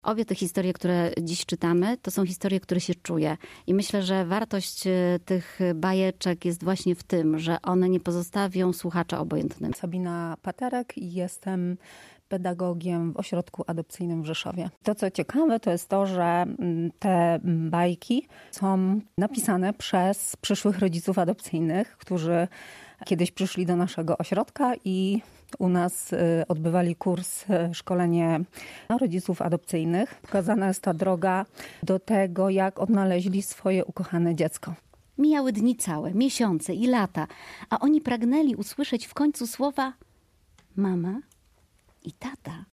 W nietypowy sposób promuje adopcję i zachęca do przyjęcia pod swój dach dzieci, które z różnych przyczyn nie mogą być ze w swoimi biologicznymi rodzinami- Ośrodek Adopcyjny w Rzeszowie we współpracy z teatrem „Maska” nagrali w Polskim Radiu Rzeszów niezwykłe bajki.